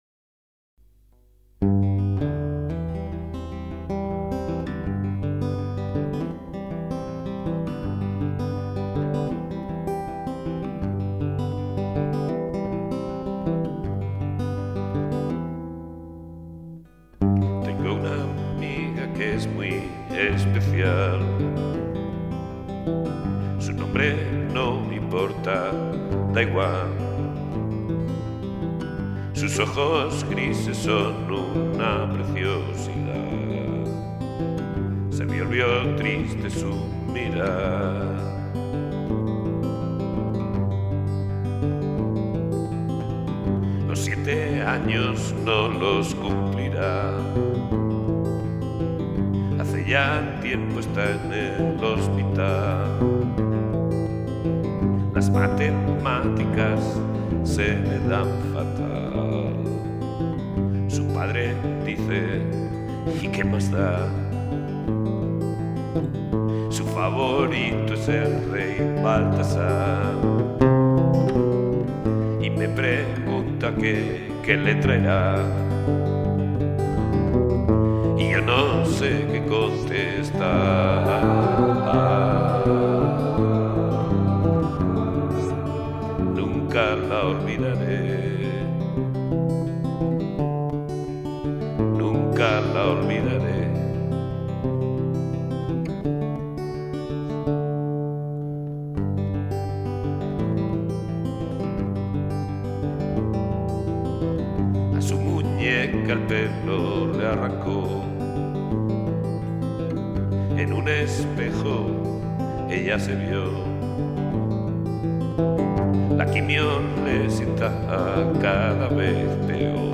Guitarra acústica y voces.
Aquí ejerzo de cantautor, sólo añadí algunos coros para disimular mi voz de Darth Vader.